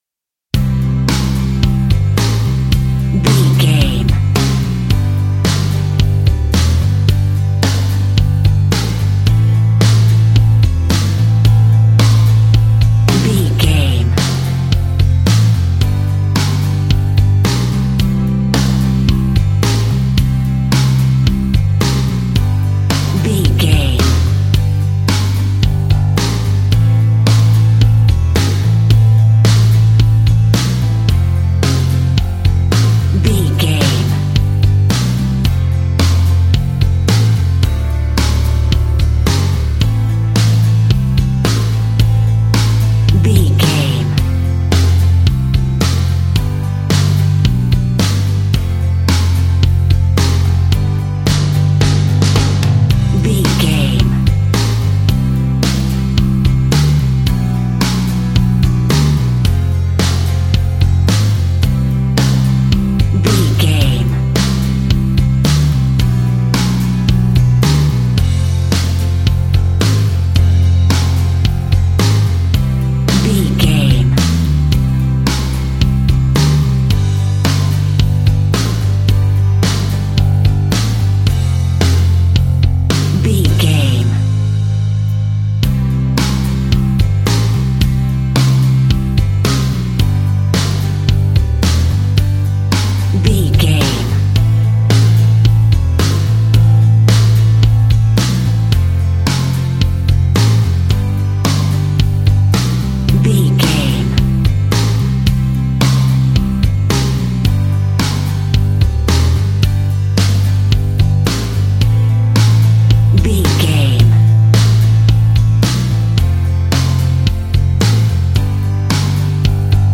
Ionian/Major
calm
melancholic
energetic
smooth
uplifting
electric guitar
bass guitar
drums
pop rock
indie pop
instrumentals
organ